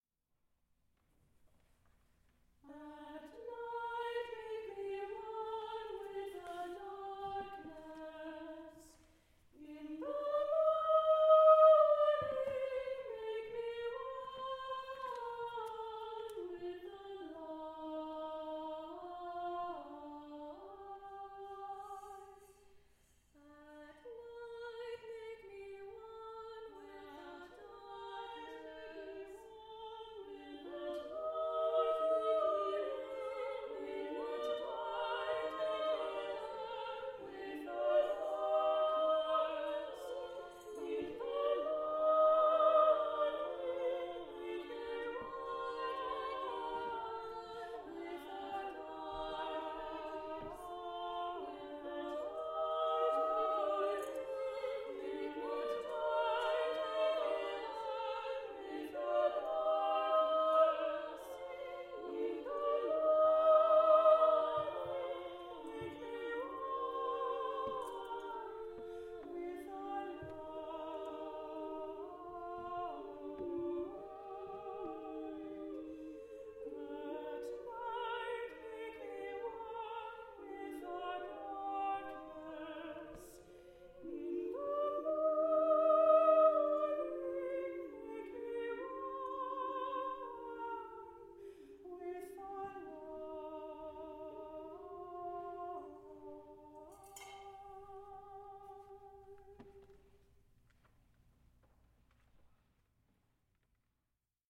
A four-part round for flexible voicing.